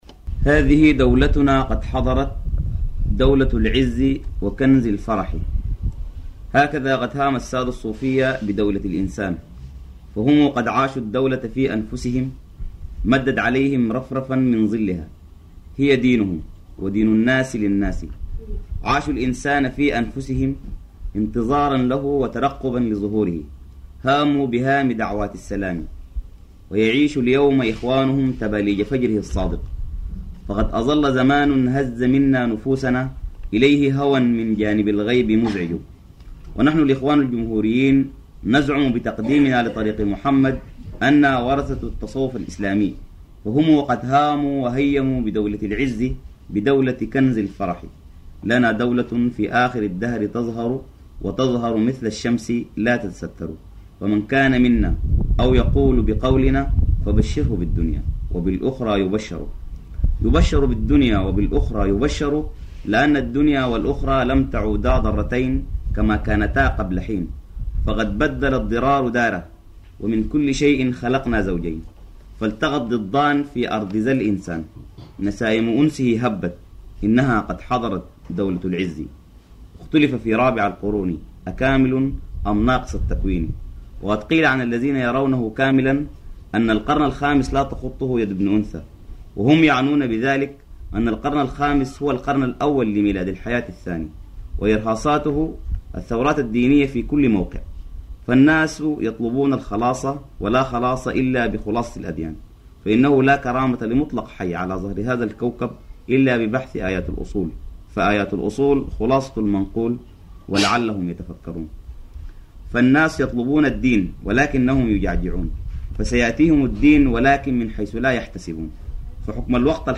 إنشاد مجموعة عطبرة
القصائد العرفانية